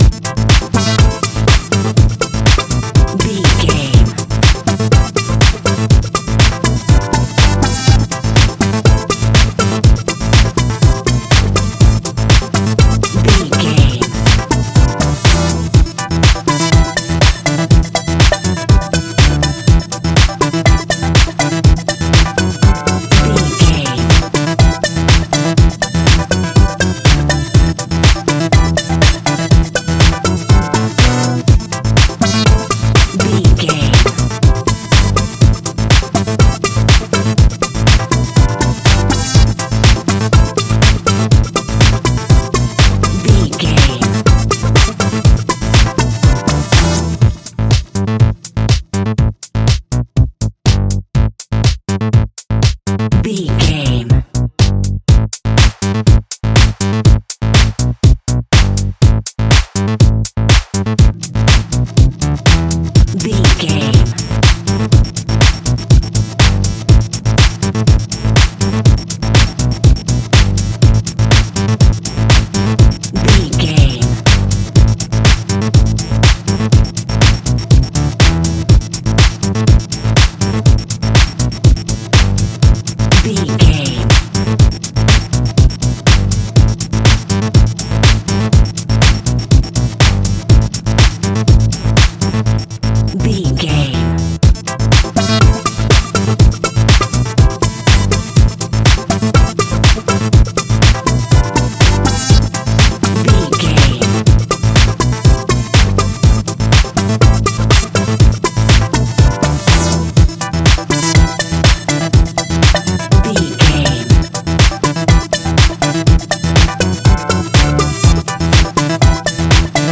Funky House music.
Aeolian/Minor
synth lead
synth bass